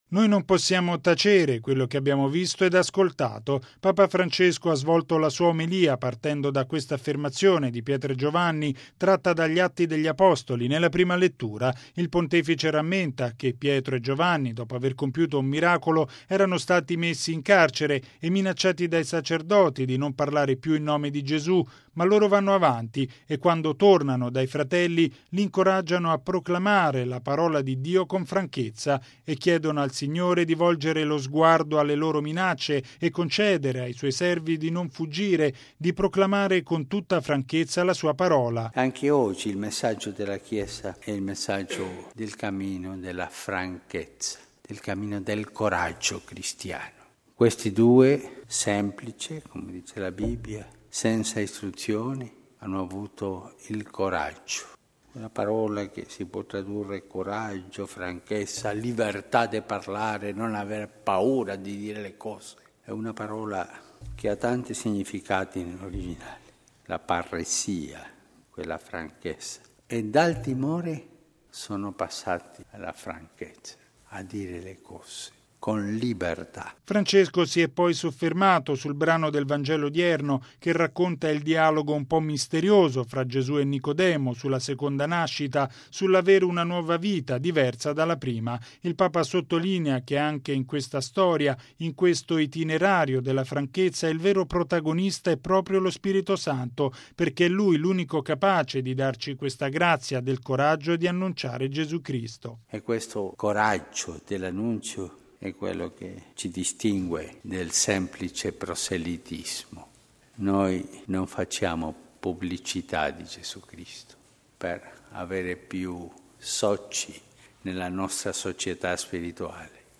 Il coraggio della franchezza: Messa a Santa Marta.